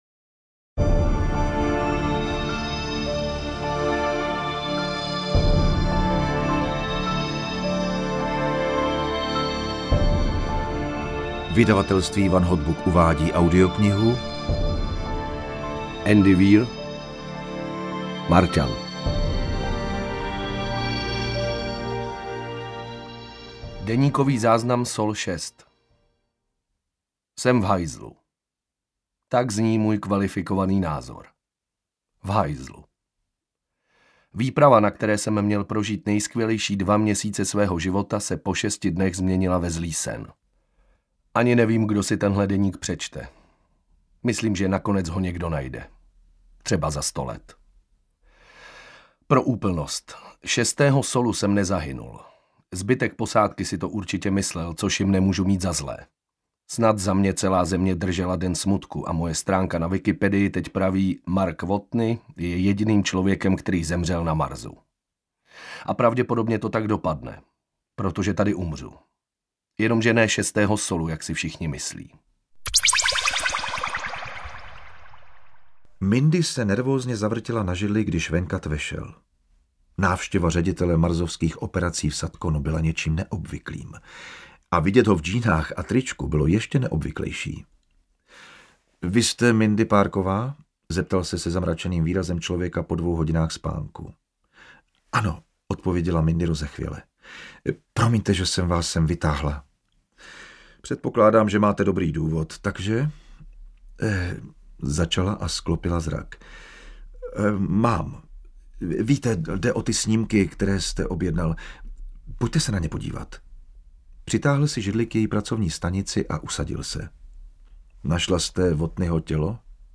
Marťan audiokniha
Ukázka z knihy